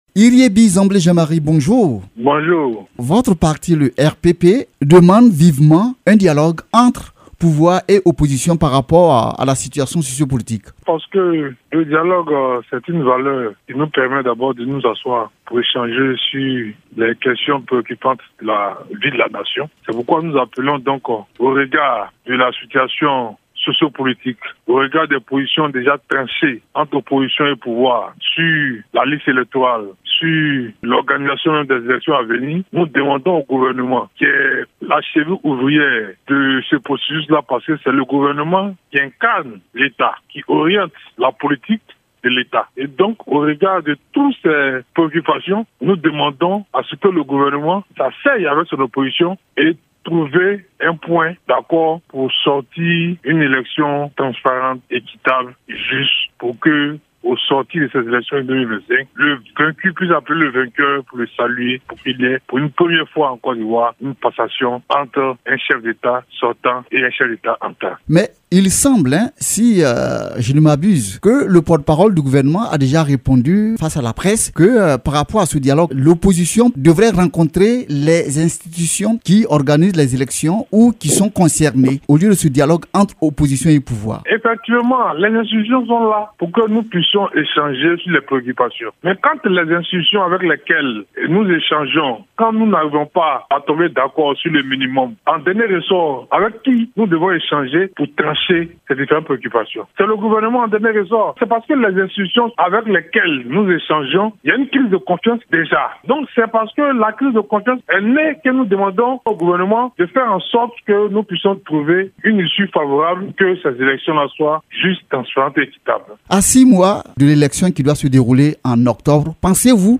Invité de la Rédaction